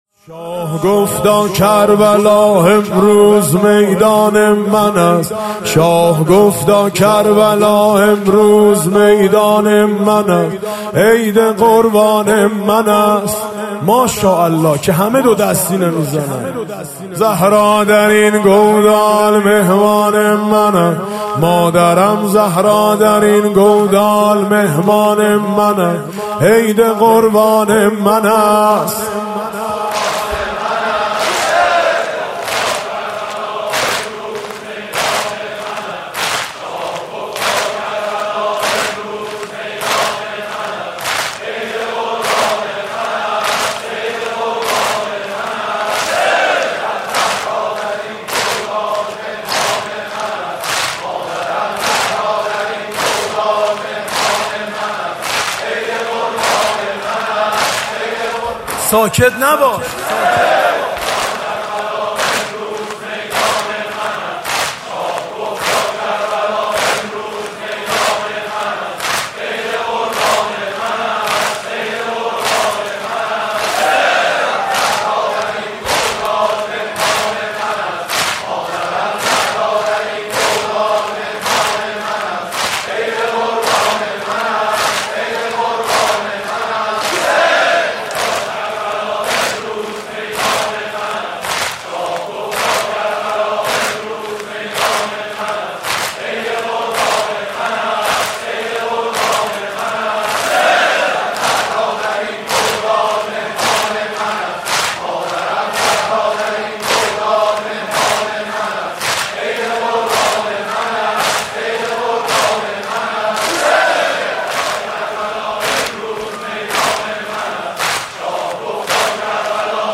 عصر عاشورا محرم ۱۳۹۹